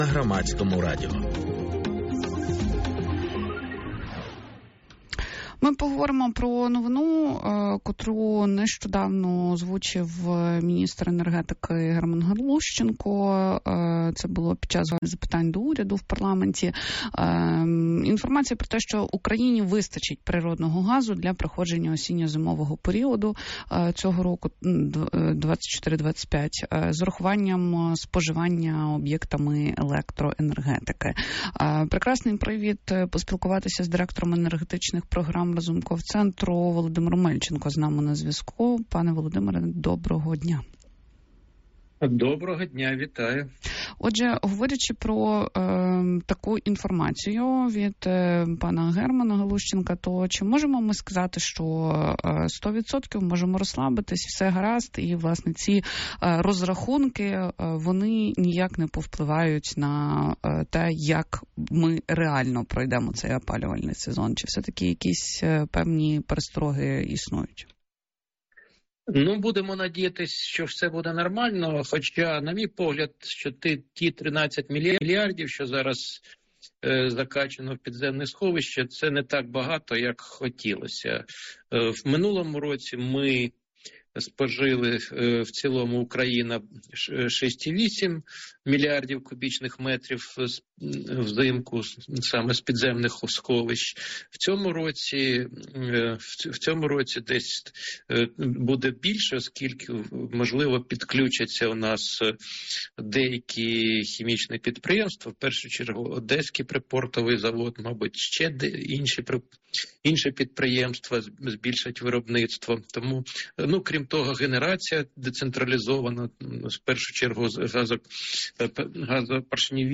в ефірі "Громадського радіо"